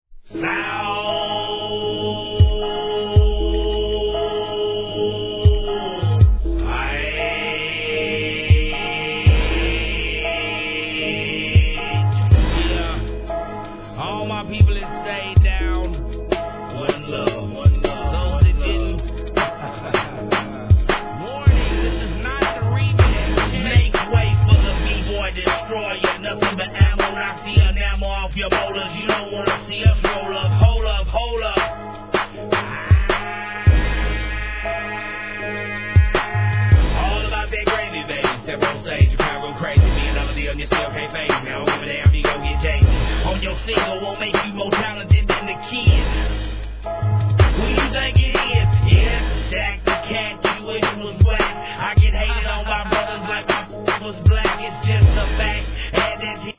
HIP HOP/R&B
ネッチョリBOUNCEの印象的なナッシュビル産!